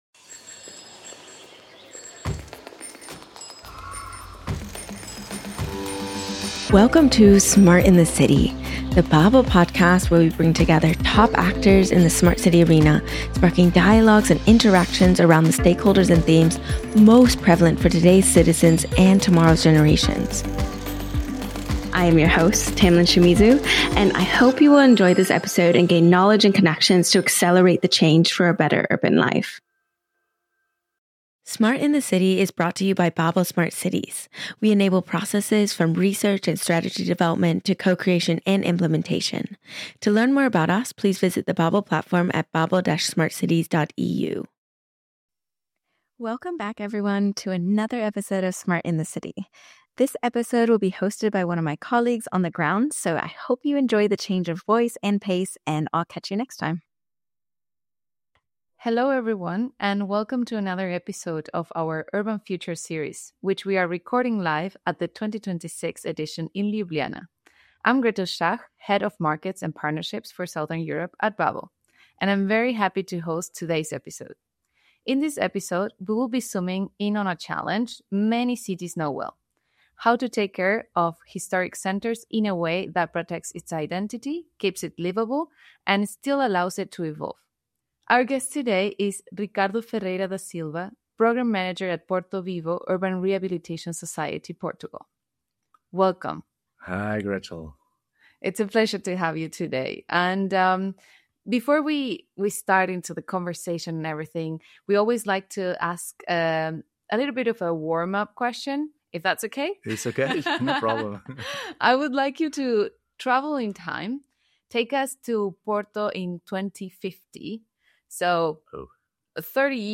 In this special episode, recorded live in collaboration with the Urban Future conference at their 2026 edition in Ljubljana, we explore the complexities of urban rehabilitation in Porto.